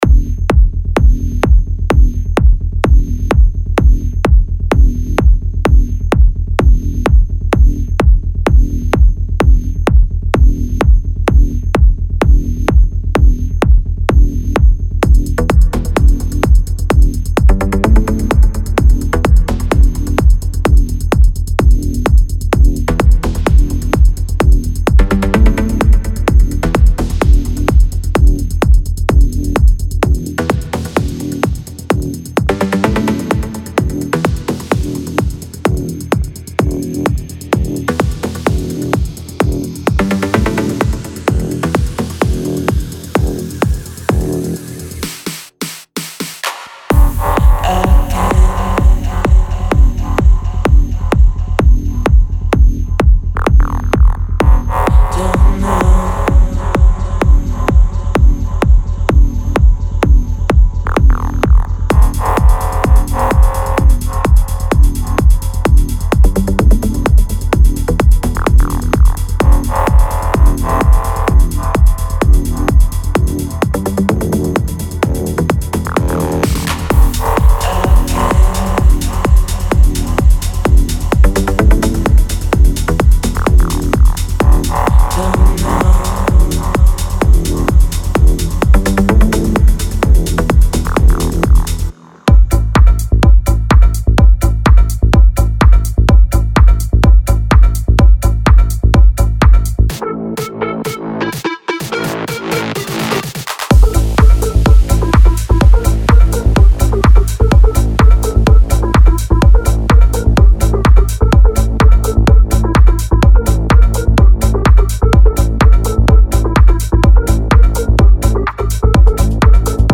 Genre: Melodic Techno Progressive House
126-132 Bpm